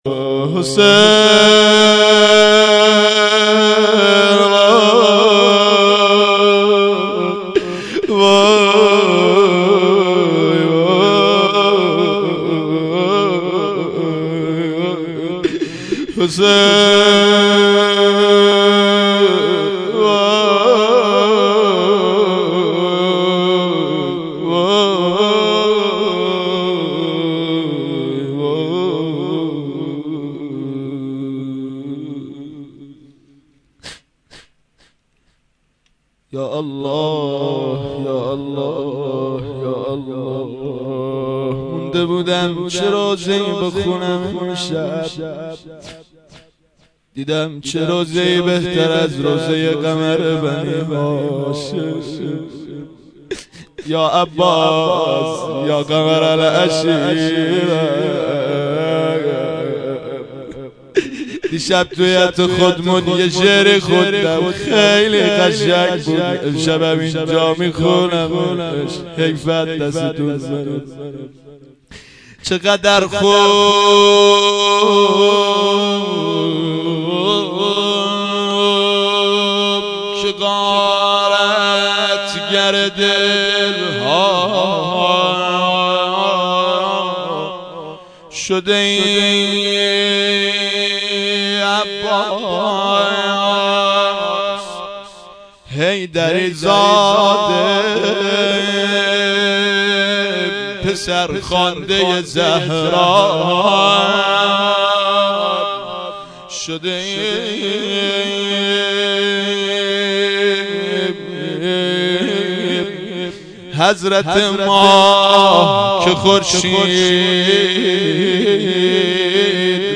haftegi 93.6.21 rozeh (2).mp3